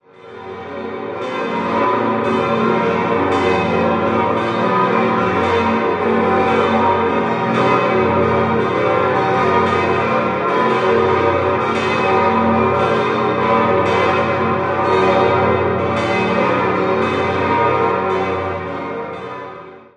Die Ausstattung selbst stammt aus mehreren Jahrhunderten. 7-stimmiges Geläute: g°-b°-c'-d'-f'-g'-b' Die Glocken wurden 1923 von der Gießerei Rüetschi in Aarau hergestellt.